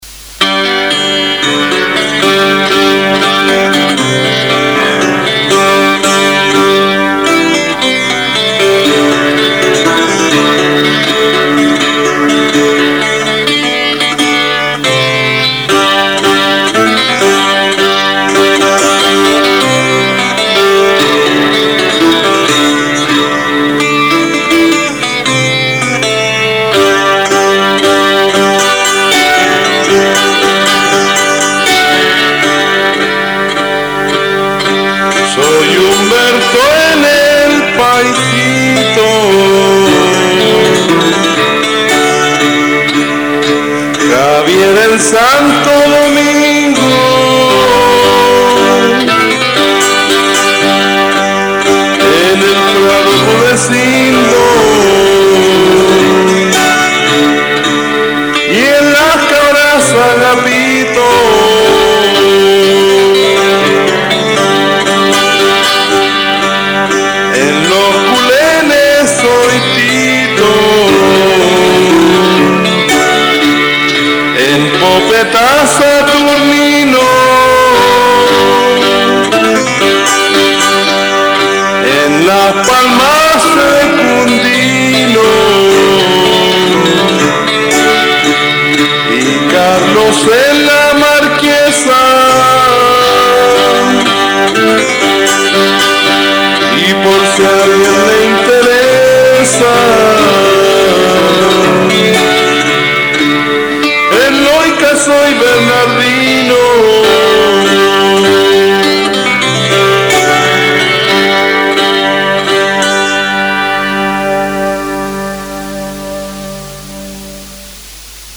guitarrón